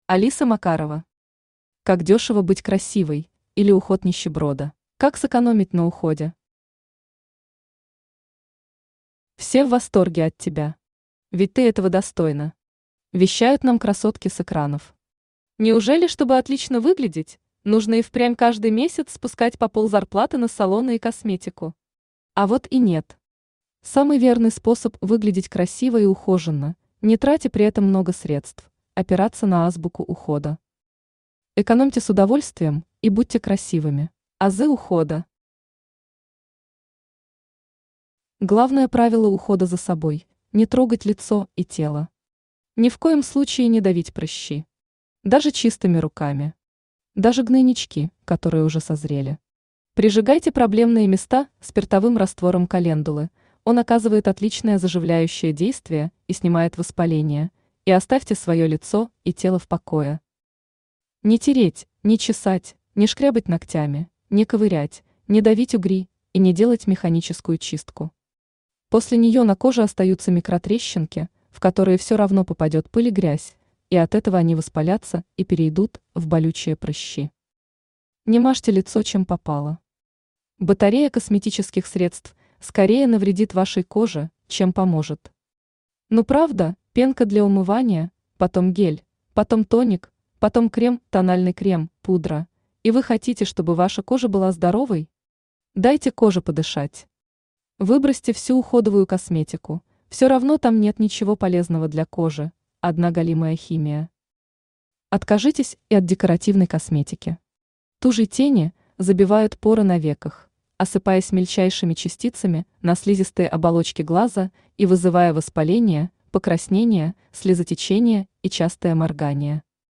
Aудиокнига Как дёшево быть красивой, или Уход нищеброда Автор Алиса Макарова Читает аудиокнигу Авточтец ЛитРес.